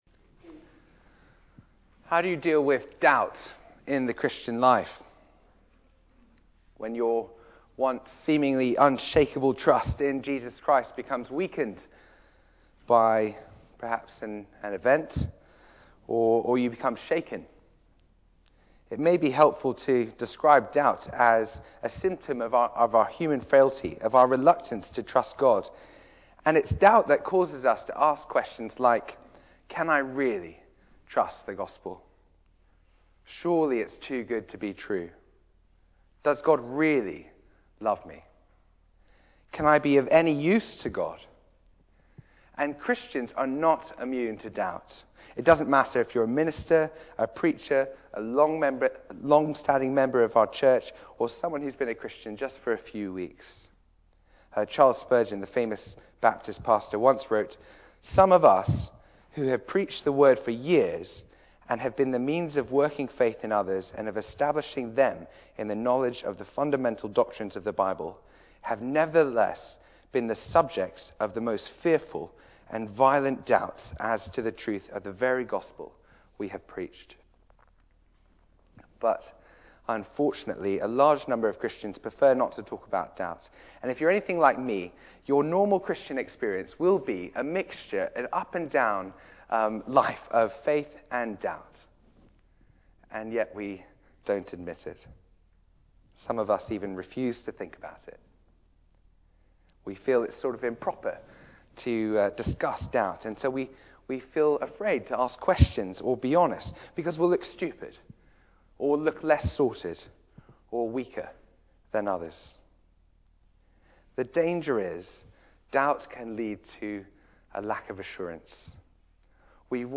Media for Arborfield Morning Service on Sun 24th Feb 2013 10:00
Theme: Mountain top experience Sermon